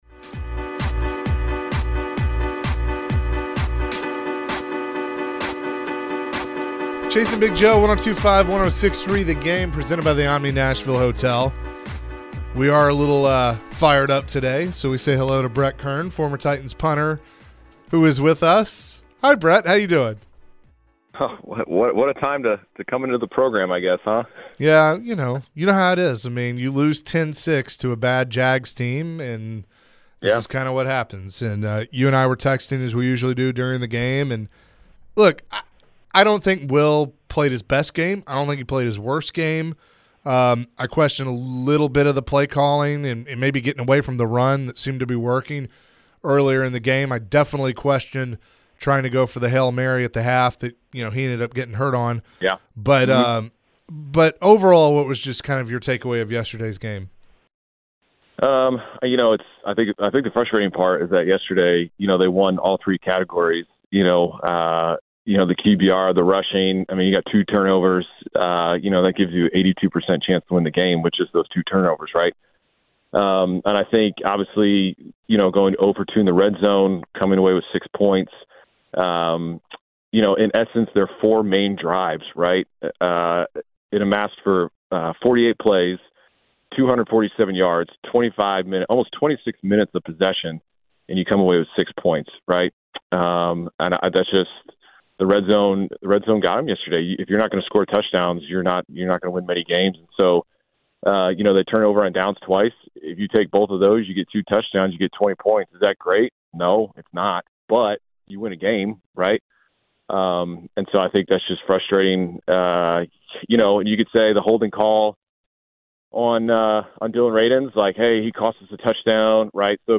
Former Titans Punter Brett Kern joined the show and shared his thoughts on the Titans' loss to the Jaguars. Brett shared his thoughts on how the game went and what all went wrong for the Titans.